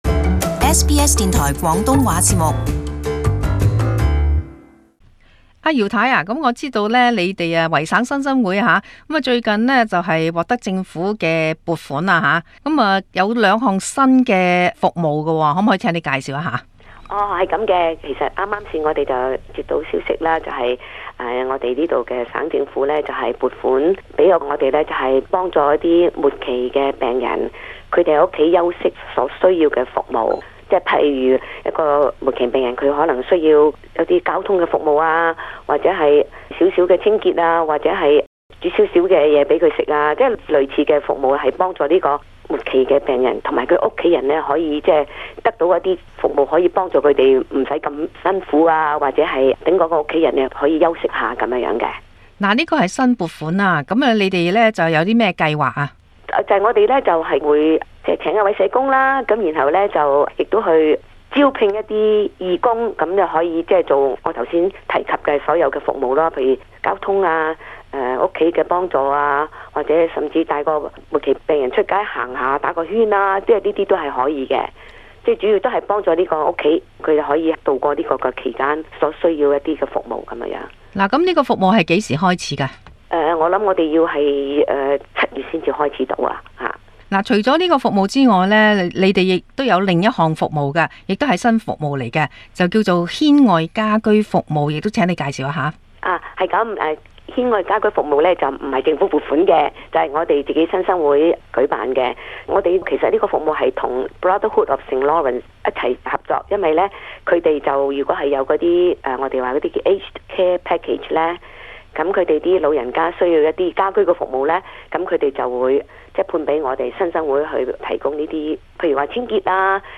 【社團專訪】軒外家居與寧養服務